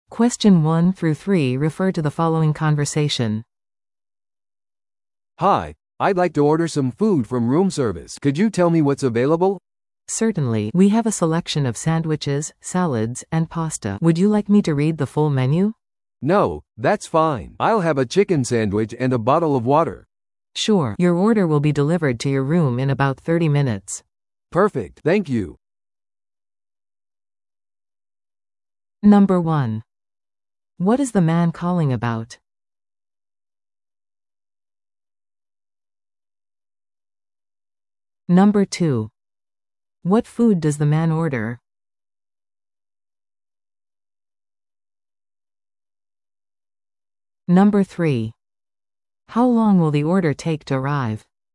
No.1. What is the man calling about?